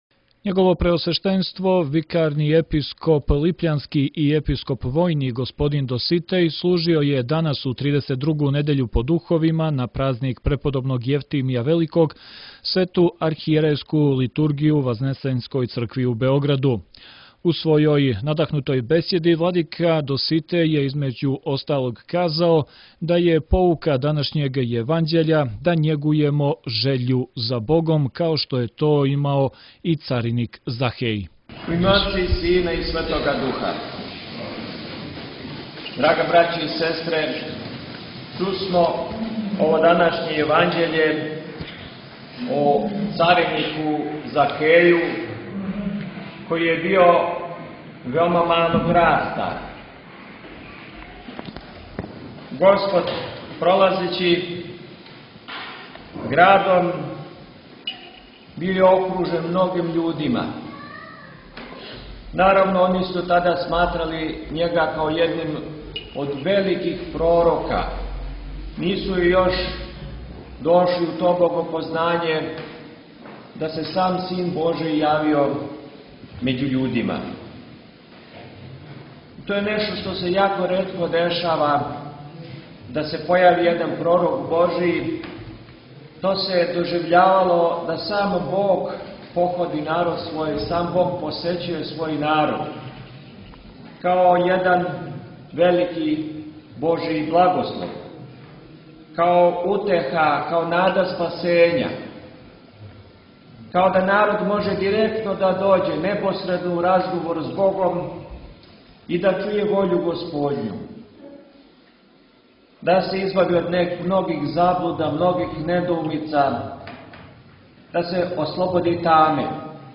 У Цркви Вазнесења Господњег у центру Београда, Свету Литургију је служио Епископ липљански Доситеј, викар Његове Светости.